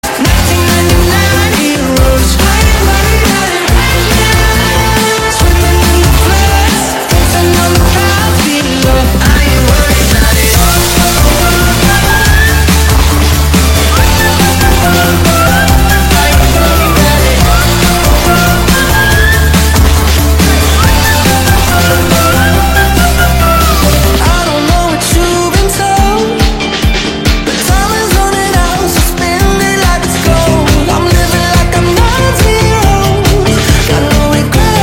Categoria POP